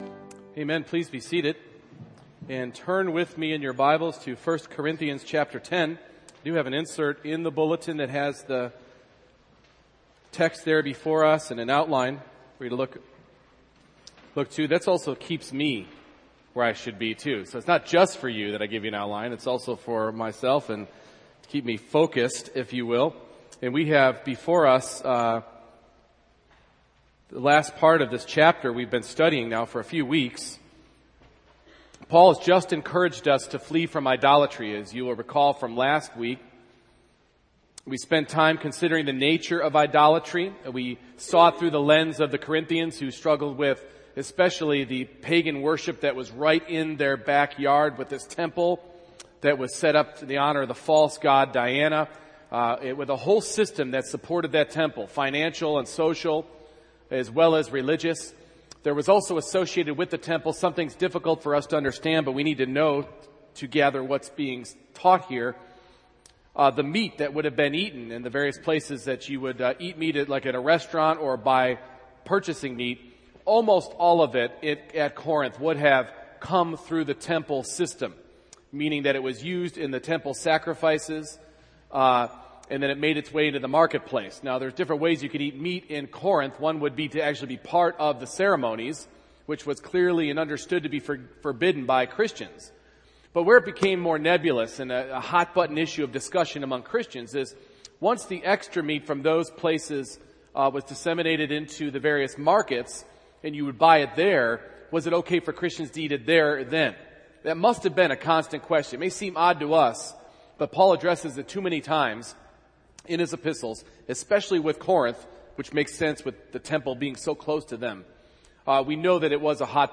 1 Corinthians 10:23-33 Service Type: Morning Worship My life